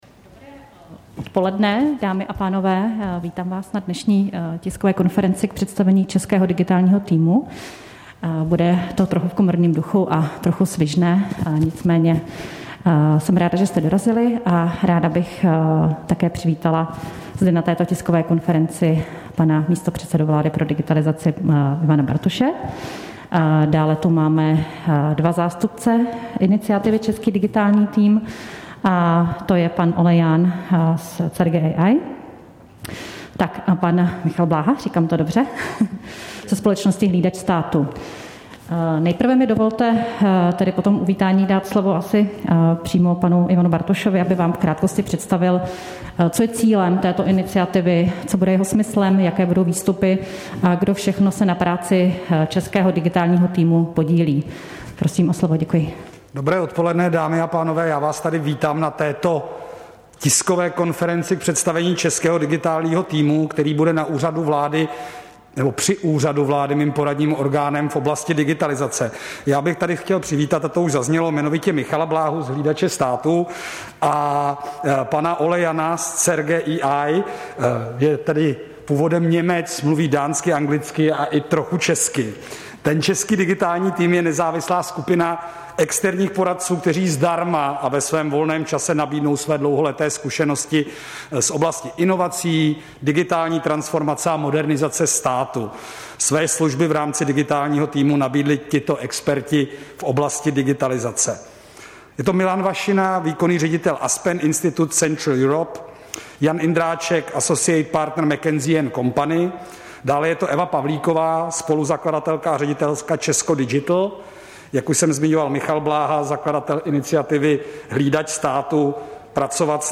Tisková konference k představení Českého digitálního týmu místopředsedy vlády pro digitalizaci Ivana Bartoše, 2. března 2022